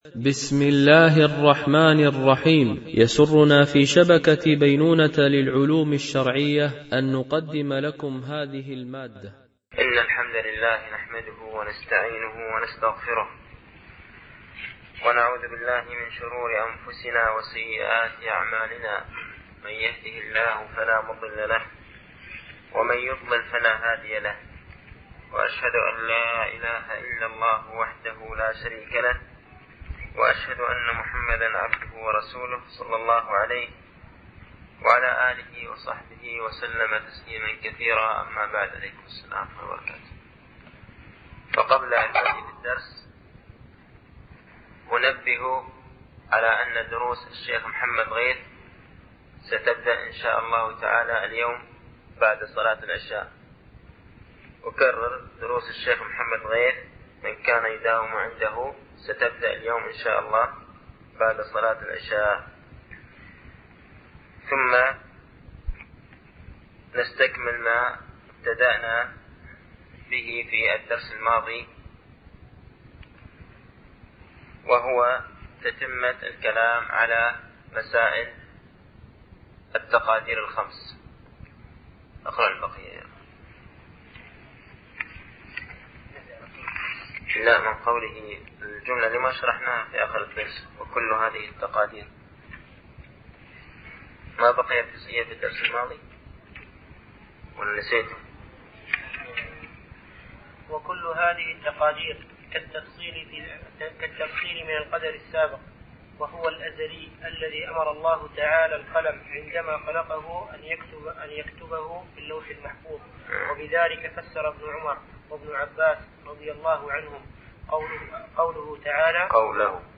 ) الألبوم: شبكة بينونة للعلوم الشرعية التتبع: 64 المدة: 69:19 دقائق (15.91 م.بايت) التنسيق: MP3 Mono 22kHz 32Kbps (CBR)